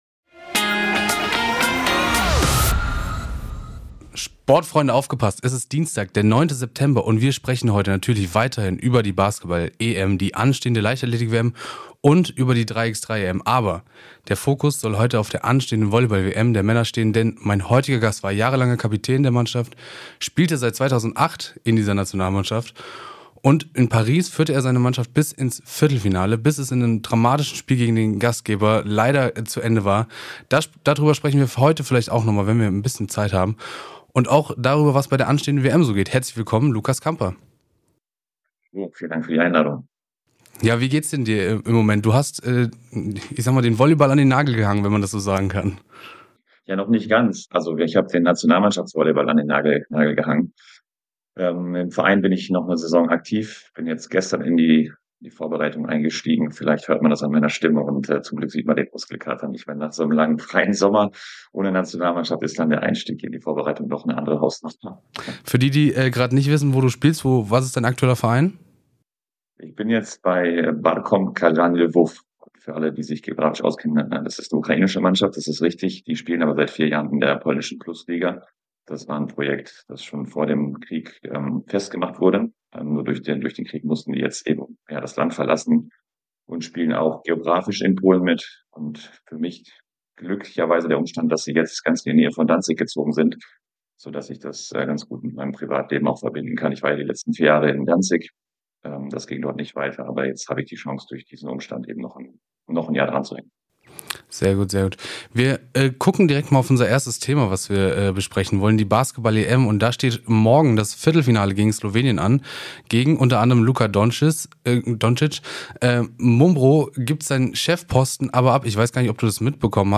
Der ehemalige Volleyball-Nationalspieler spricht mit uns über die Basketball-EM, die anstehende Volleyball-WM und die 3x3-EM in Kopenhagen. Locker, ehrlich und mit jeder Menge Insider-Perspektive – so hört ihr Sport-Updates am liebsten.